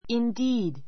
indeed A2 indíːd イン ディ ー ド 副詞 実に, 全く, 本当に, 実際 He is indeed a clever boy.